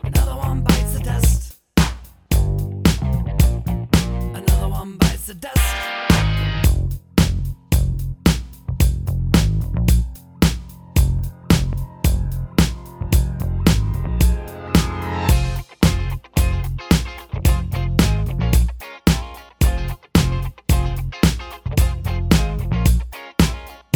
Minus Lead Guitar Rock 3:31 Buy £1.50